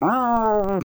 sounds / monsters / cat